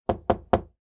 Knock On Door.wav